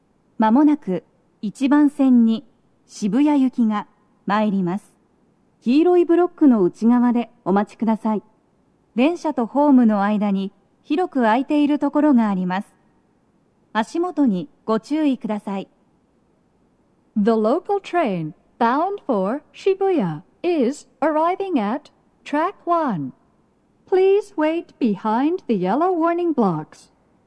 スピーカー種類 BOSE天井型
鳴動は、やや遅めです。
1番線 渋谷方面 接近放送 【女声